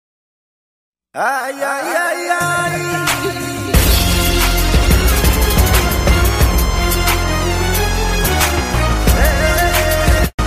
Jojos Bizarre Adventure ay ay sound effects free download
Jojos Bizarre Adventure ay ay ay ay - Meme Sound Effect